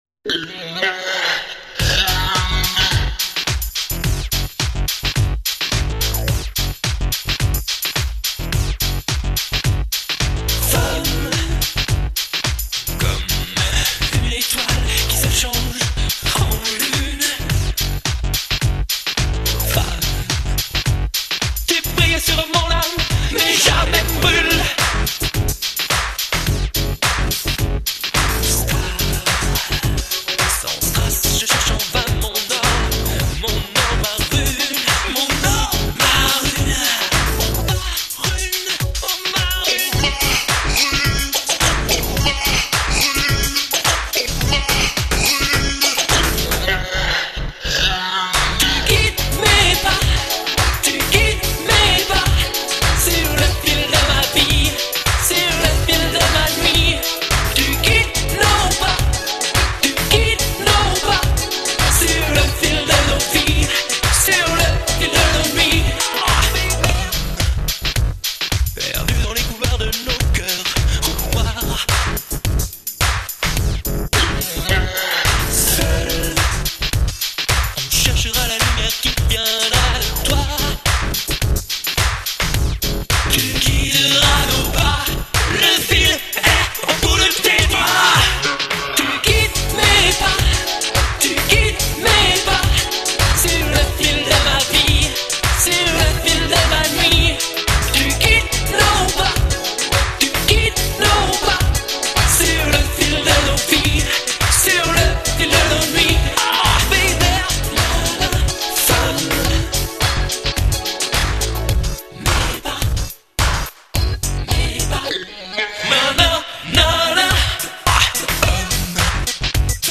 avec un ton résolument pop
Très influencé par le son anglo-saxon